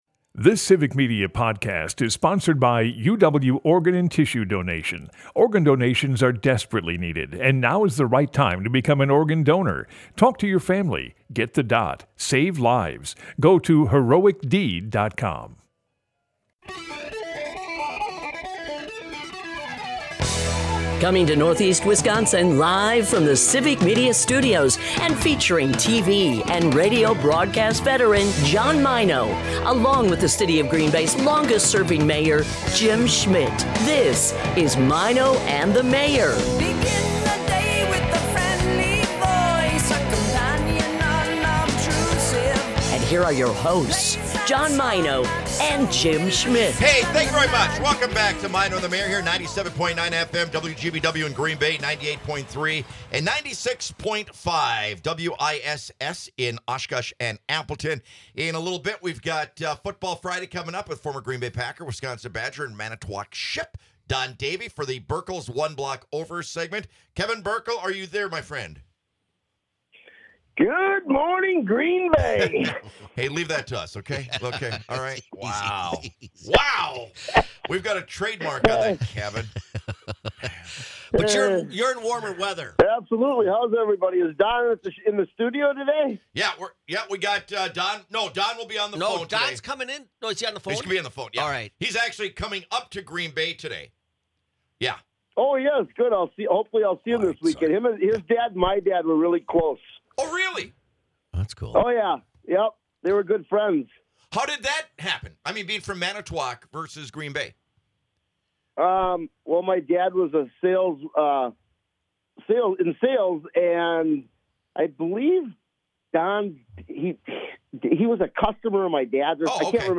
Experience the chemistry and humor between two great friends. Broadcasts live 6 - 9am in Oshkosh, Appleton, Green Bay and surrounding areas.